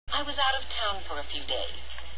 When ¡§of¡¨ follows a consonant of the proceeding word, the /f/ sound disappears and the vowel // moves over to the consonant before it and makes a new syllable.